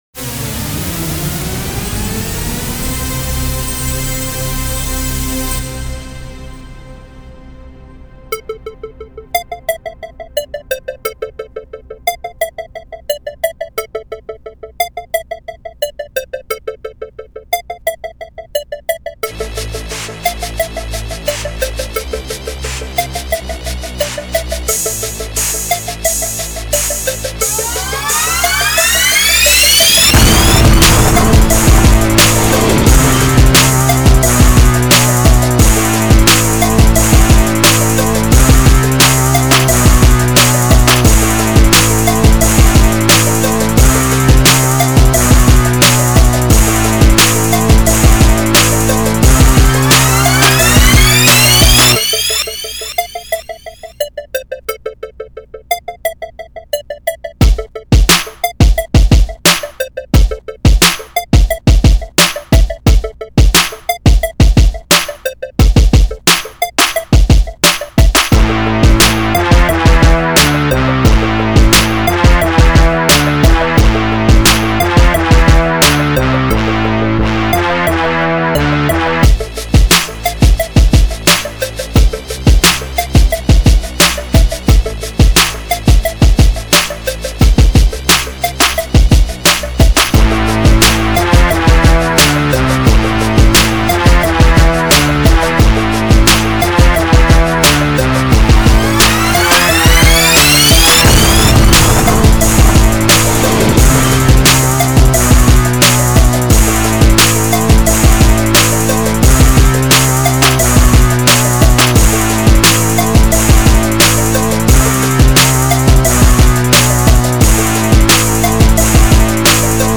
შესვლა  HipHop,Rap Instrumental